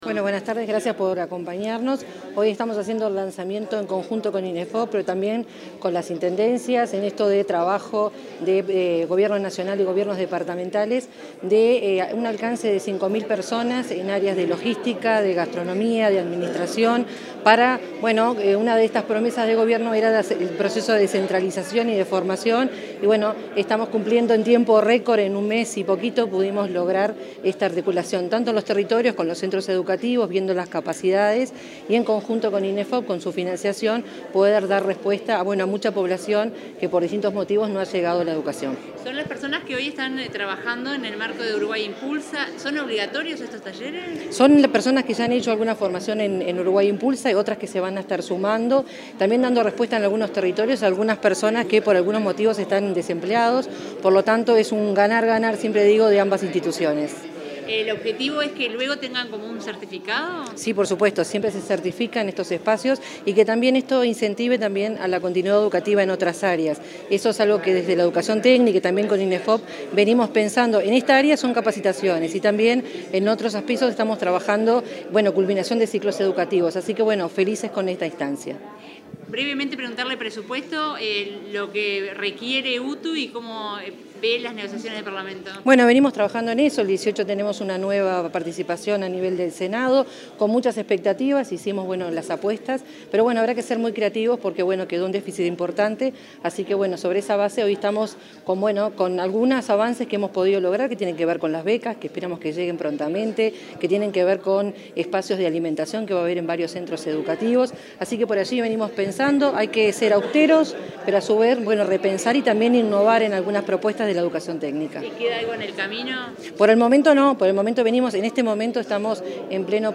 Declaraciones de la directora general de Educación Técnico Profesional, Virginia Verderese